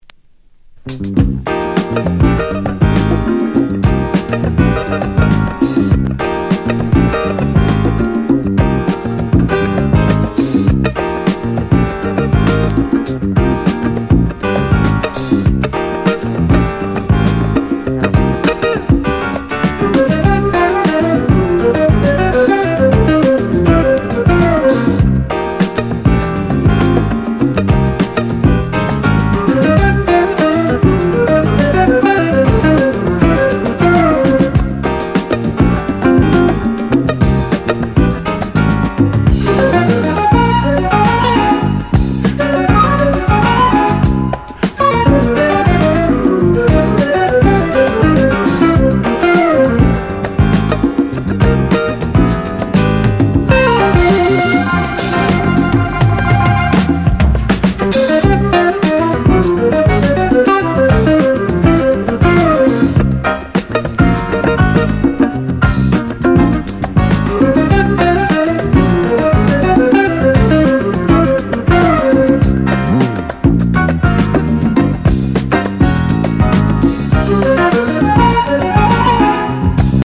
プログレッシヴ・フルート！
• 特記事項: STEREO